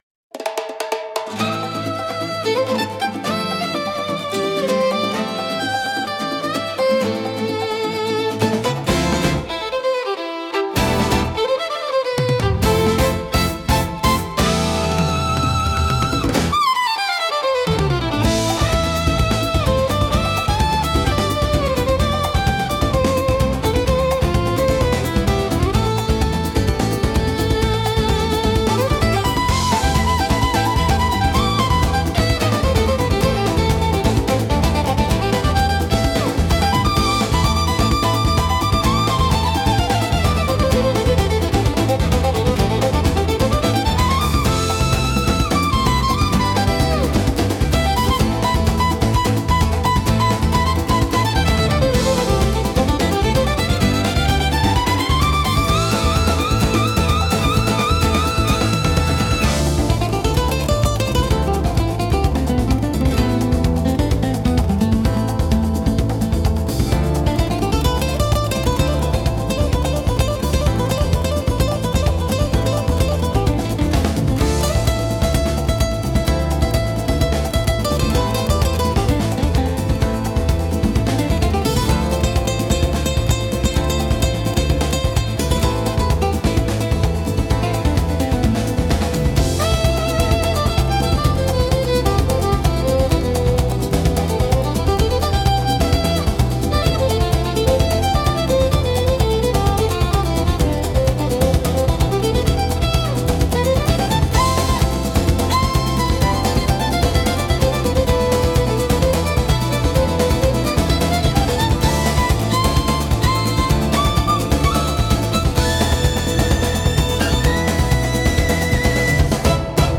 An uplifting track with the pleasant sound of violin 🎻
バイオリンの音色が心地よく響く、アップテンポで踊りやすい一曲🎻
軽快なリズムと爽やかなメロディが、自然と体を動かしてくれます。